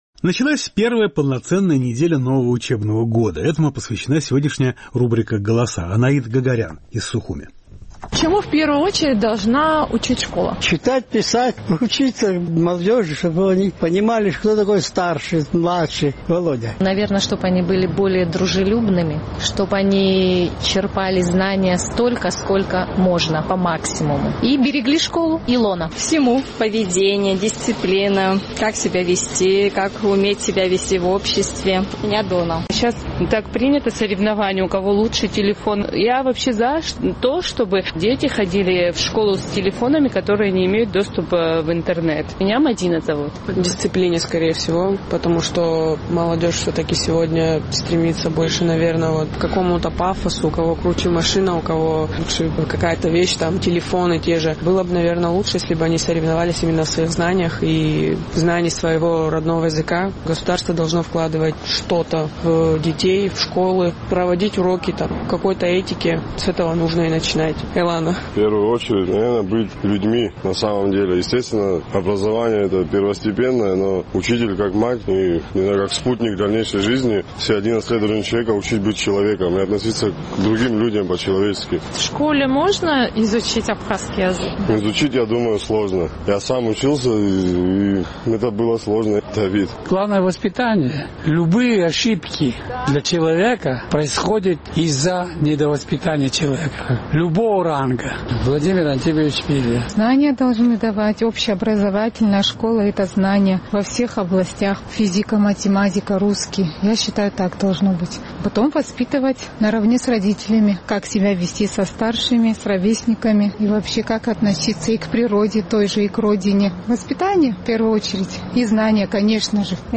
Наш сухумский корреспондент поинтересовалась у жителей абхазской столицы, чему в первую очередь должна учить школа и на каком уровне находится обучение абхазскому языку.